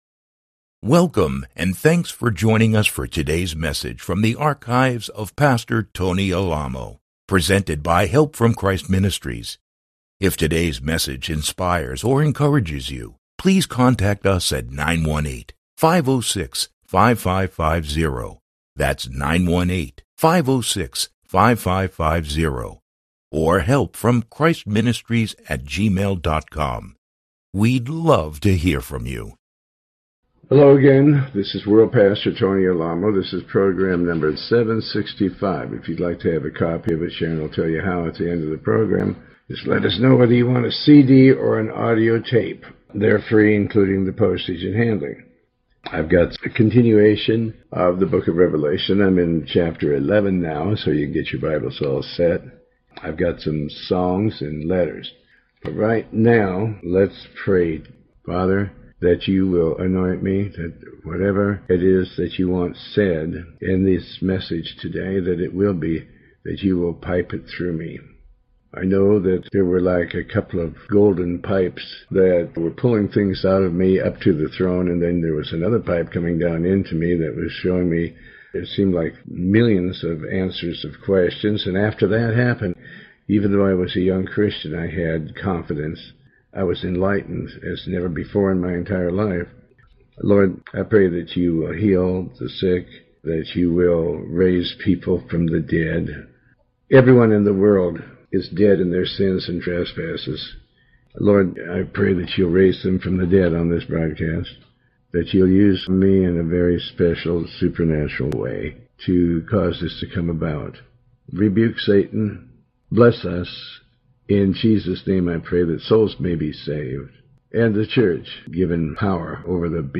Talk Show Episode
Pastor Alamo reads and comments on the Book of Revelation chapter 11.